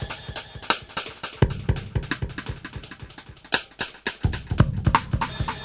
Index of /musicradar/dub-drums-samples/85bpm
Db_DrumsA_KitEcho_85_02.wav